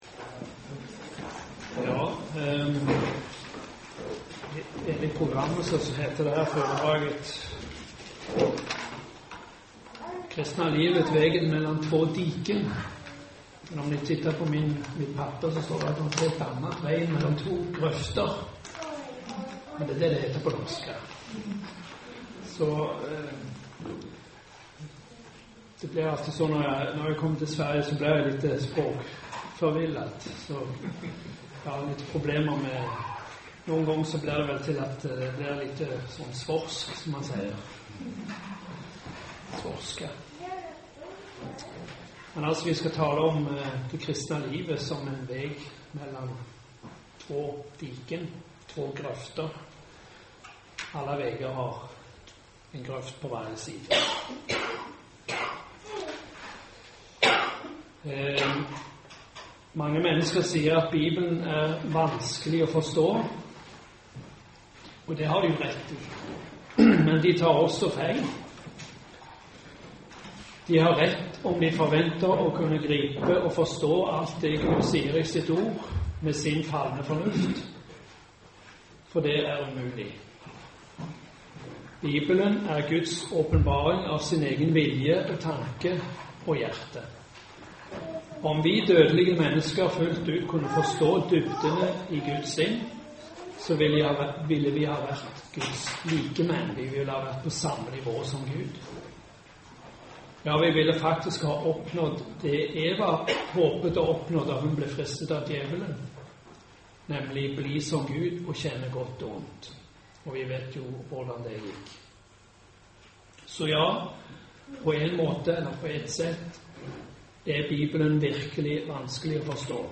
Föredrag under Biblicumhelg i Norrköping, Hemgården, 13 april 2013.